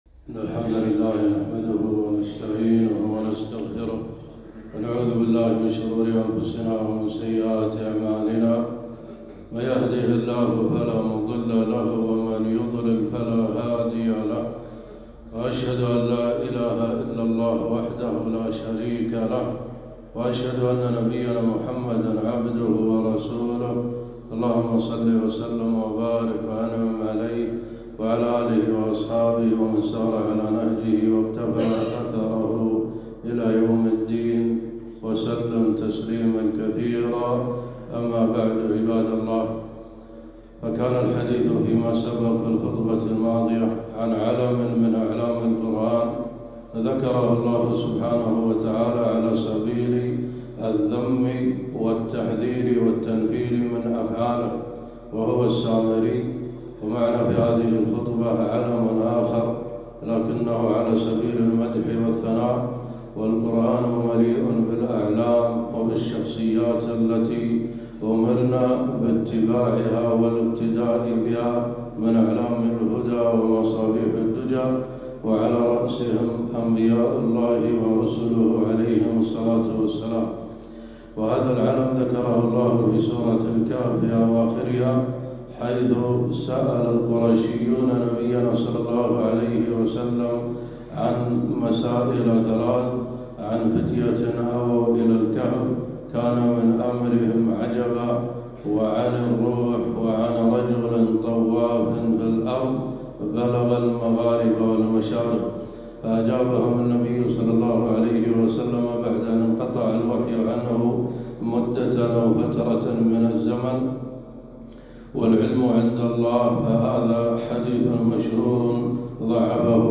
خطبة - ( ويسألونك عن ذي القرنين )